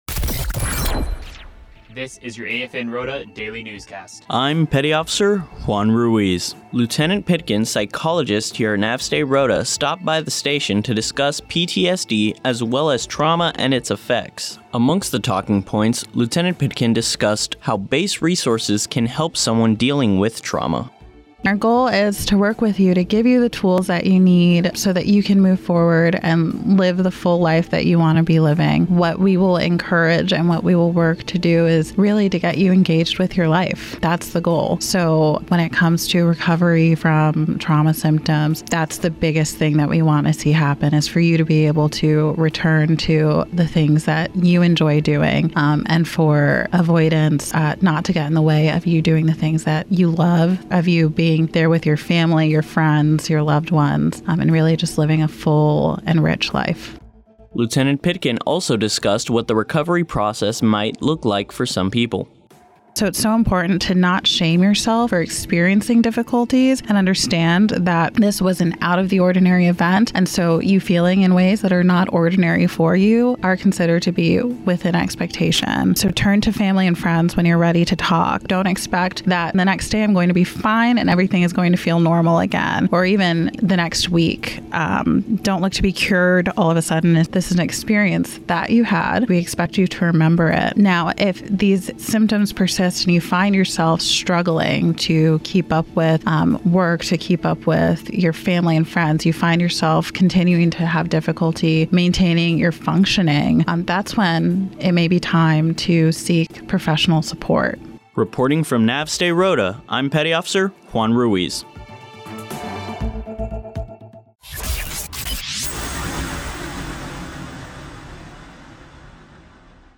Eagle Rota News newscast